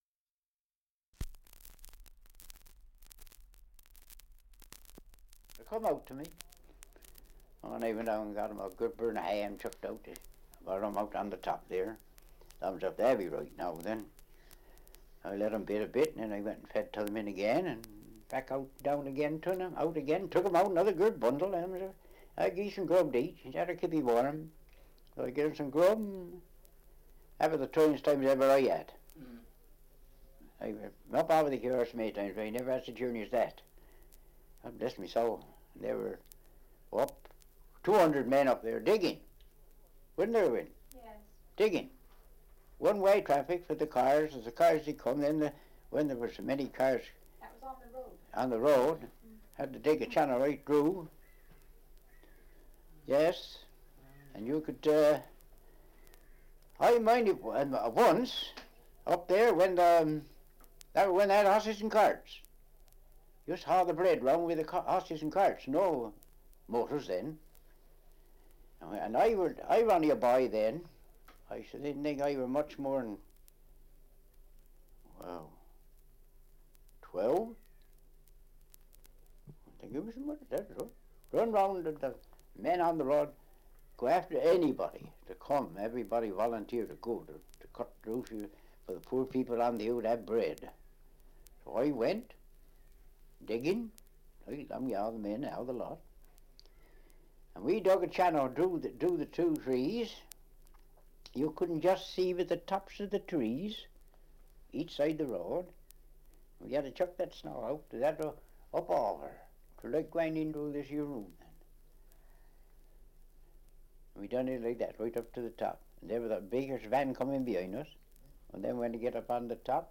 2 - Survey of English Dialects recording in Blagdon, Somerset
78 r.p.m., cellulose nitrate on aluminium